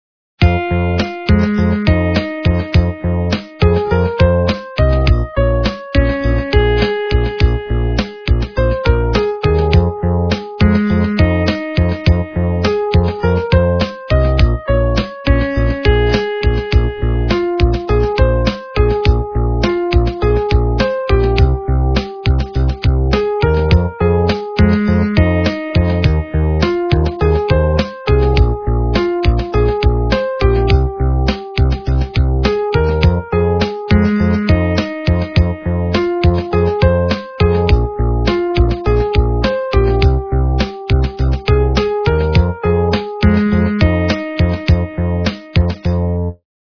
западная эстрада
качество понижено и присутствуют гудки
полифоническую мелодию